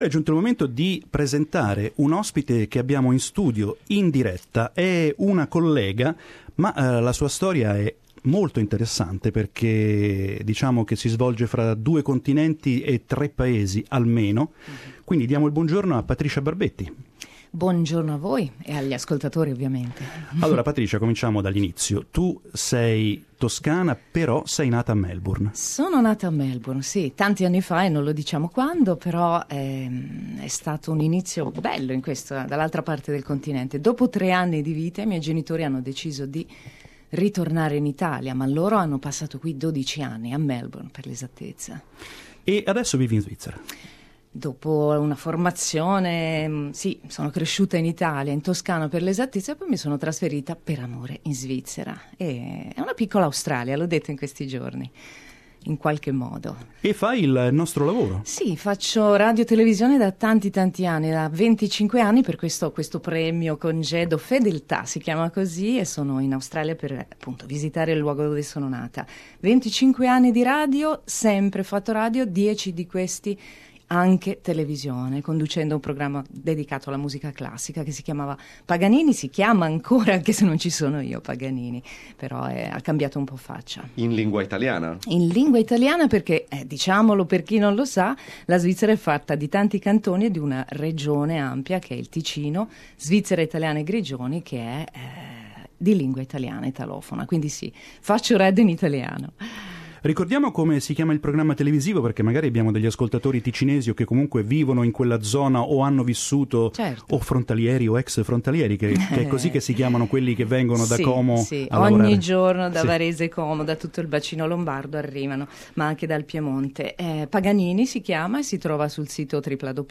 We spoke to her during a visit to our Sydney studios.